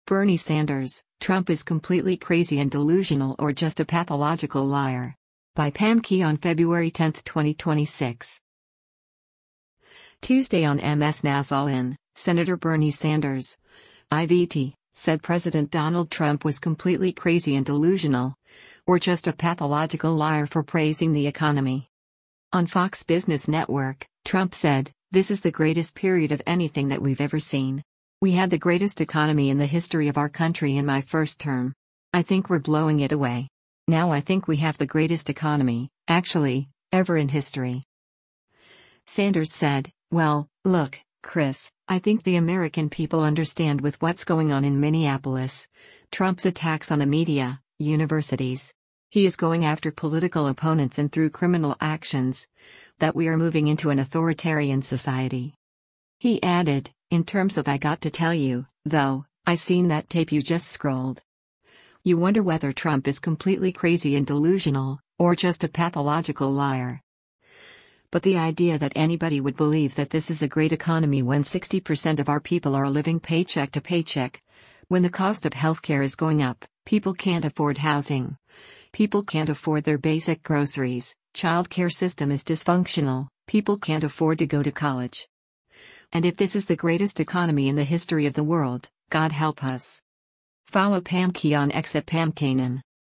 Tuesday on MS NOW’s “All In,” Sen. Bernie Sanders (I-VT)  said President Donald Trump was “completely crazy and delusional, or just a pathological liar” for praising the economy.